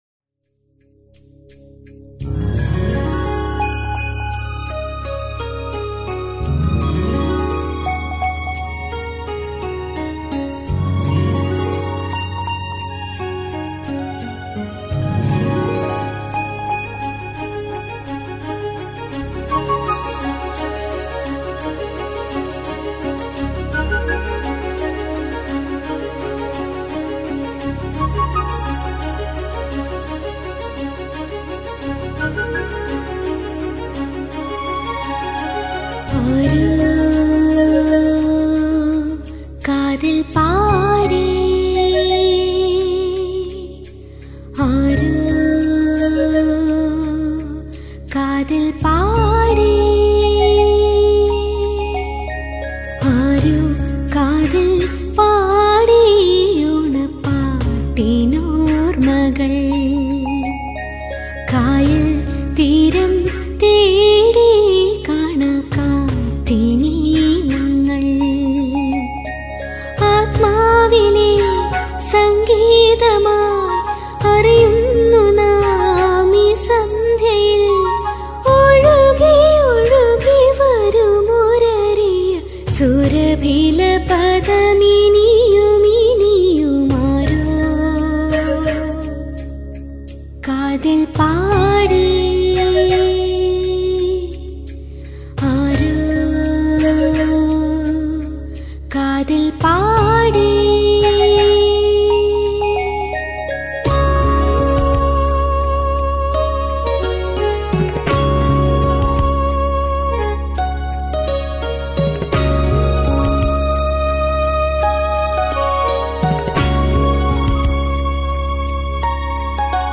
A soothing song
very nice! very good sound